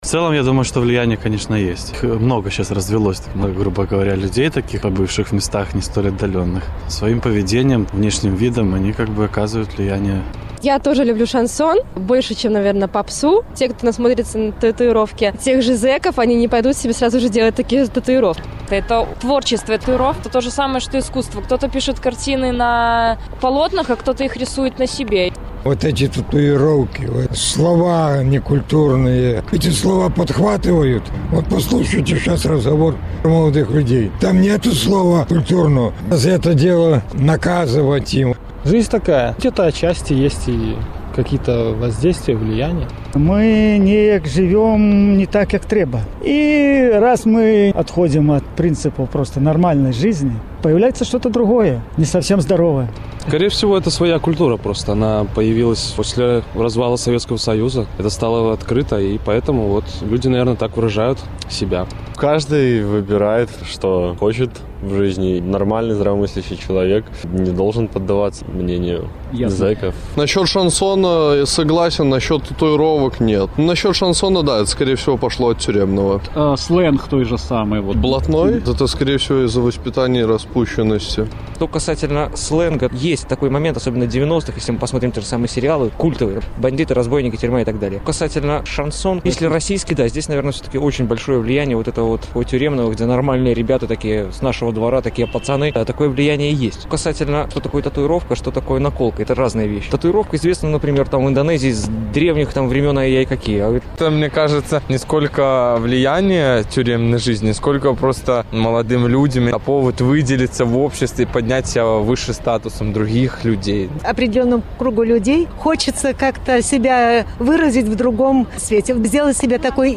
Embed share Ці ўплывае турэмная культура на масавую? Адказваюць магілёўцы by Радыё Свабода || Радио Свобода Embed share The code has been copied to your clipboard.